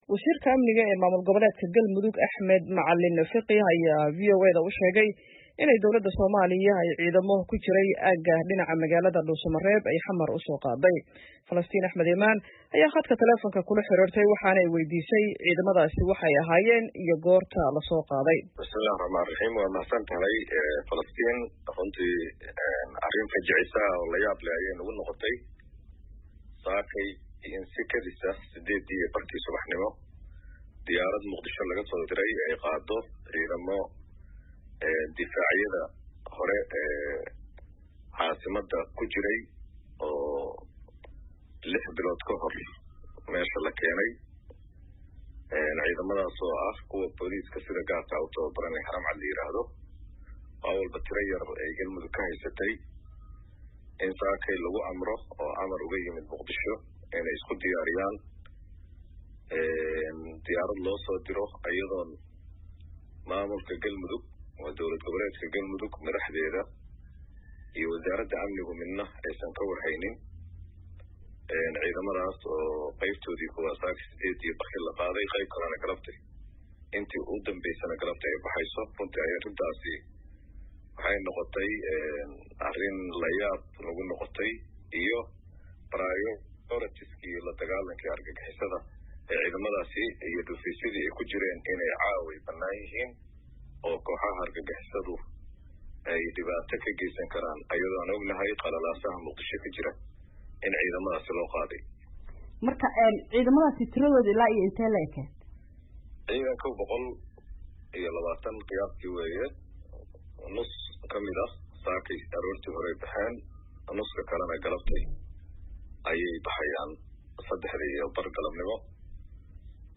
khadka telefoonka kula xiriirtay